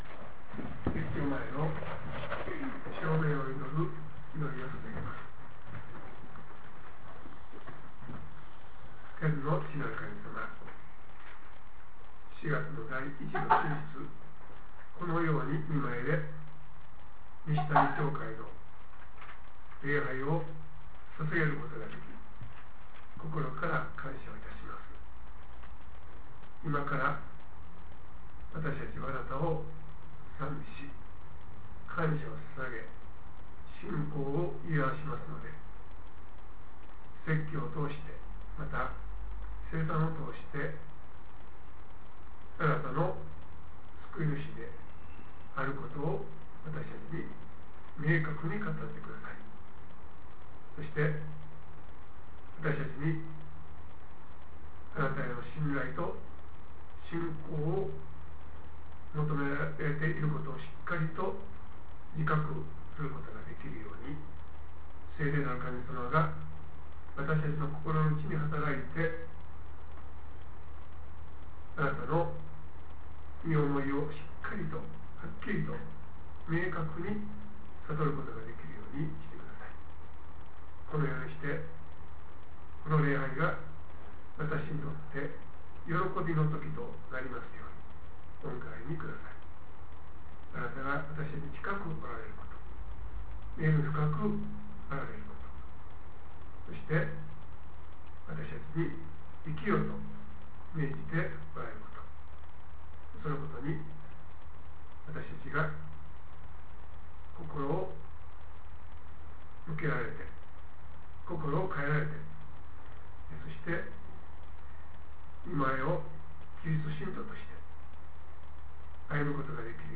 2014年４月６日説教